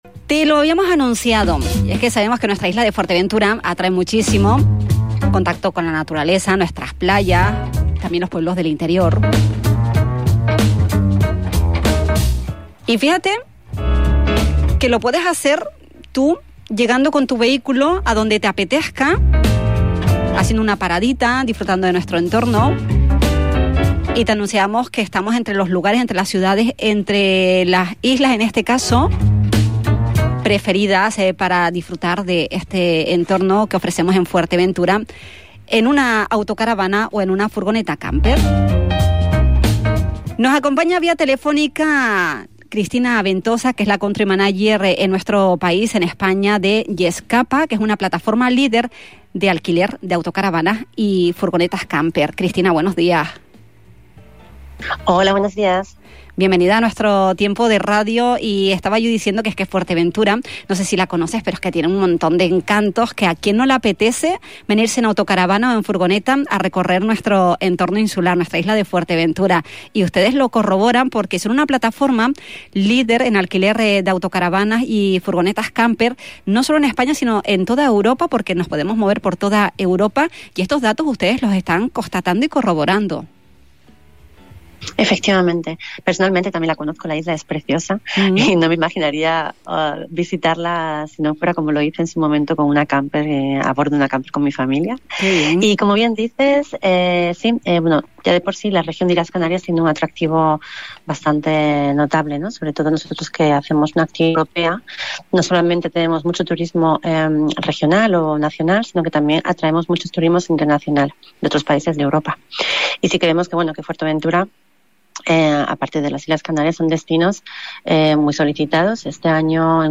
En una animada conversación telefónica en La Mañana Xtra de Radio Insular